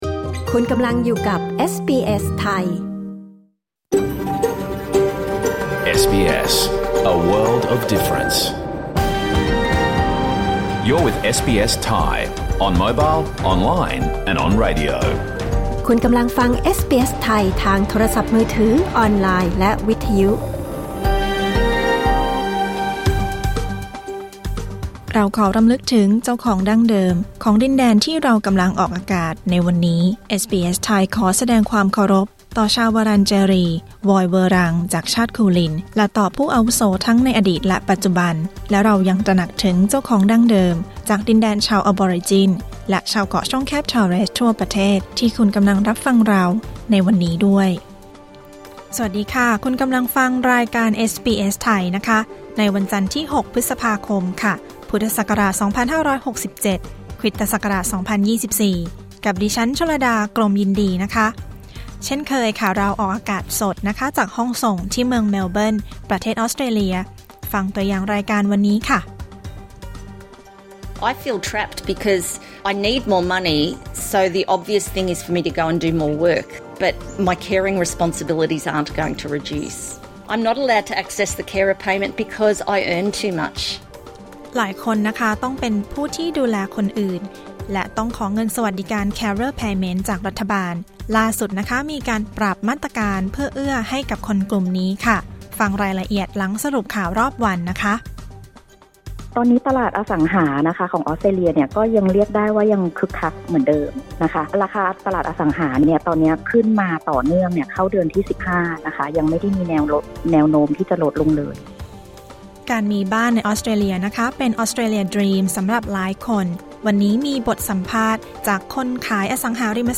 รายการสด 6 พฤษภาคม 2567